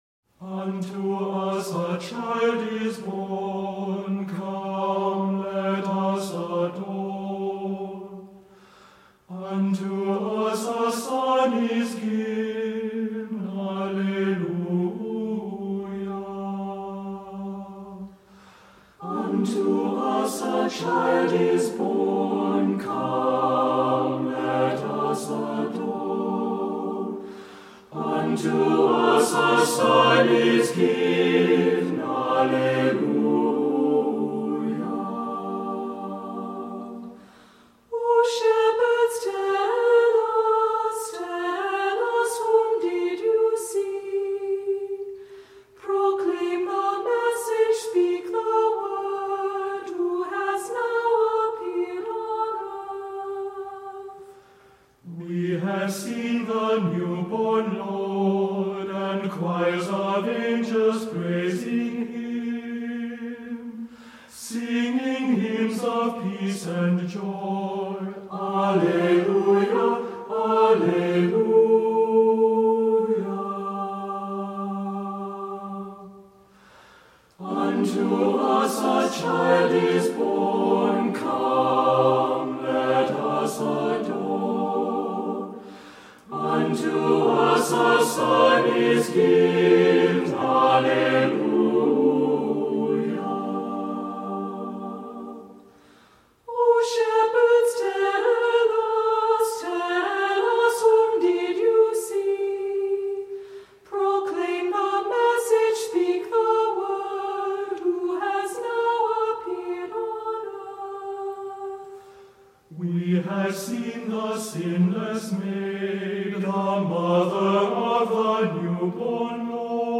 Voicing: SATB, optional assembly